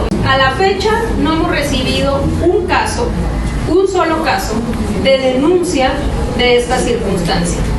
En conferencia de prensa, donde acompañó a la candidata por Morena por la alcaldía de Xalapa, Daniela Griego, afirmó que las acusaciones son de "gente que están lastimados".